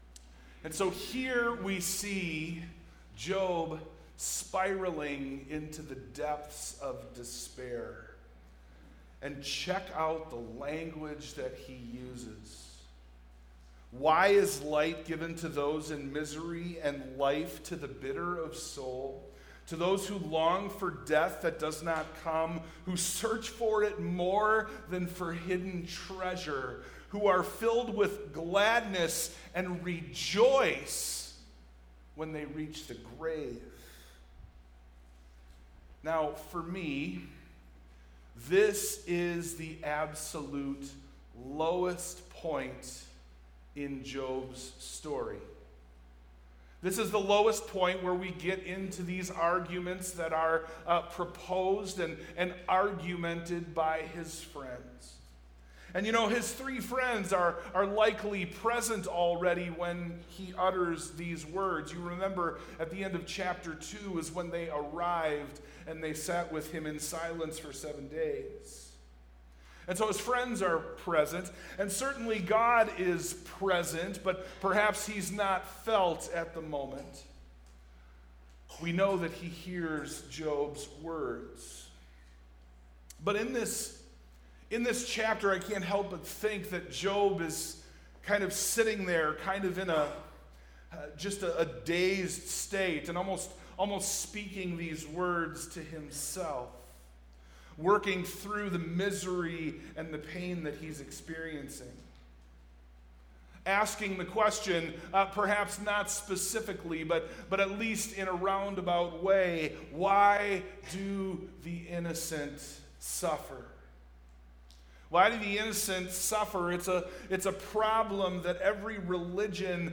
Service Type: PM
Sermon+Audio+-+Depths+of+Despair.mp3